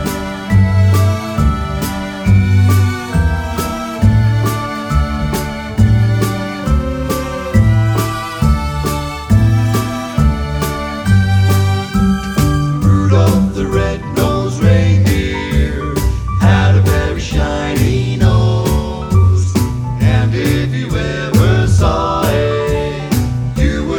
no Backing Vocals Crooners 2:14 Buy £1.50